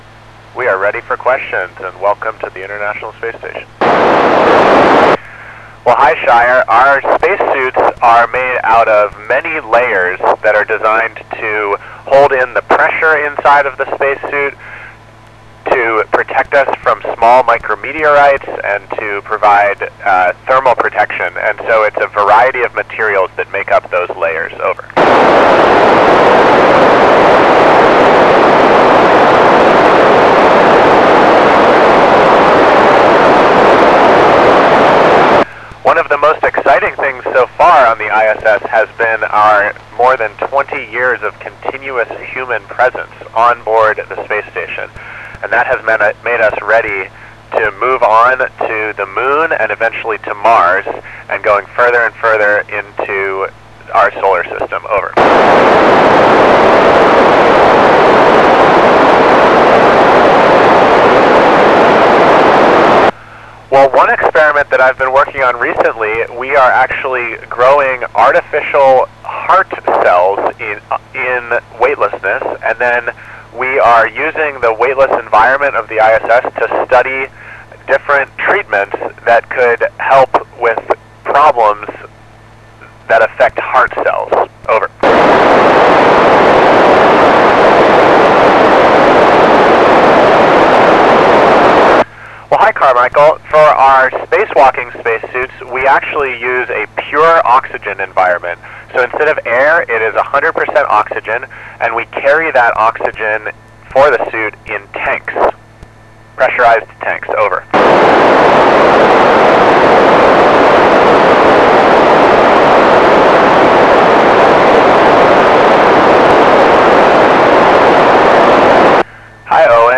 Random Selected Radio Contacts via ISS NA1SS FM Repeater
Jasmin Moghbeli Random Selected Parts School Contacts ( Astronaut )